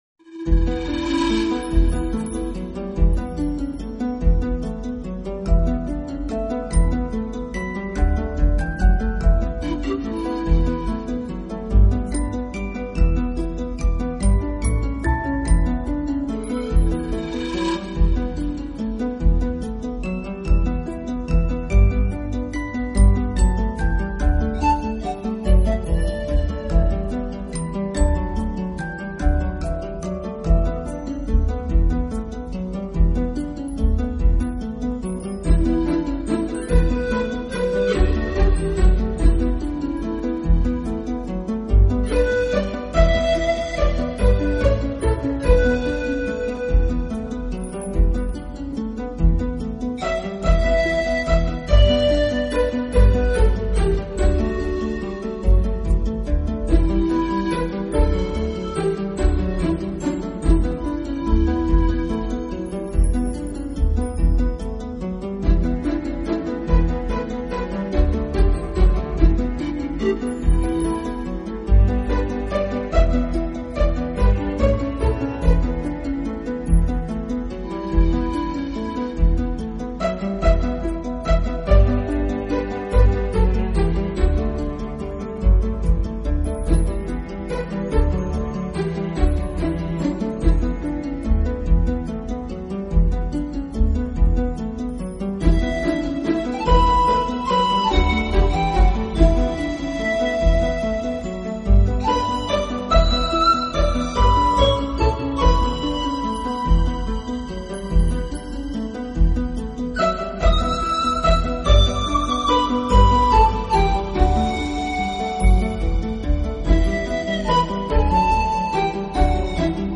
自然音乐
纯音乐
最时尚的休闲背景音乐